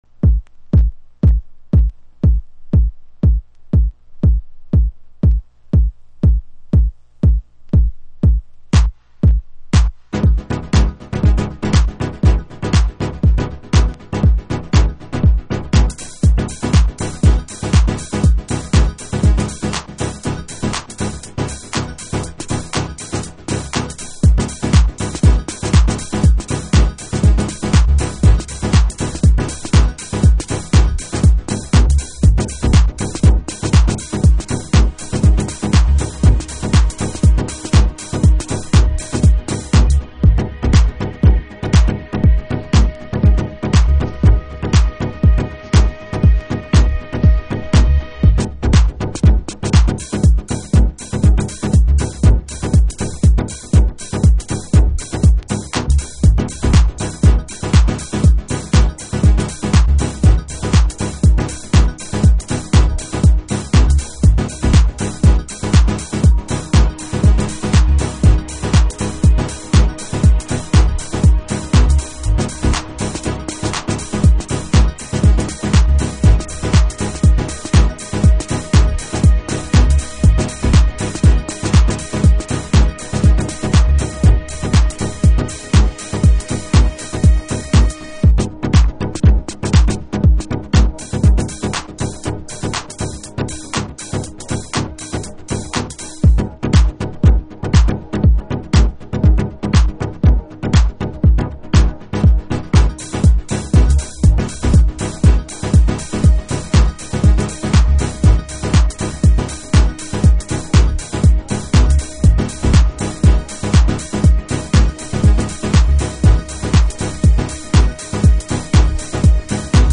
Detroit House / Techno
昨今珍しいヴォーカルサイドとダブサイドに別れた12inch。